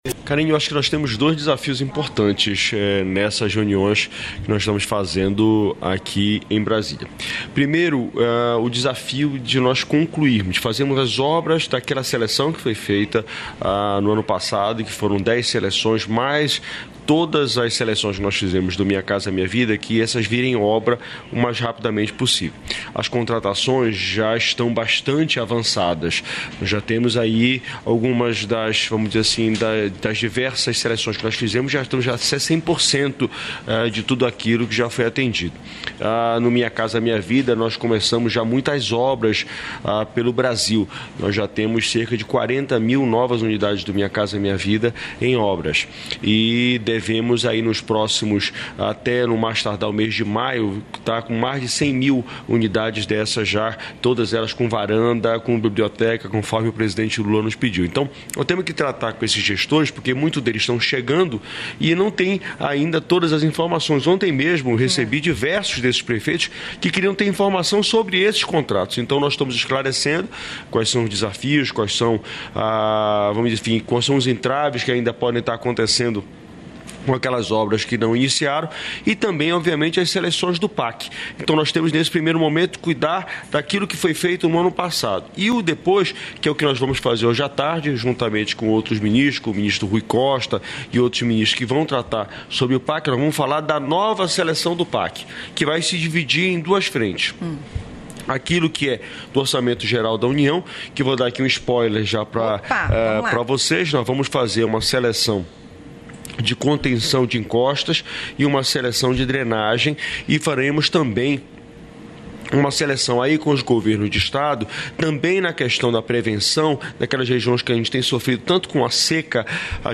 Trecho da participação do ministro das Cidades, Jader Filho, no programa "Bom Dia, Ministro" desta quarta-feira (12), nos estúdios da EBC no Encontro de Novos Prefeitos e Prefeitas, em Brasília.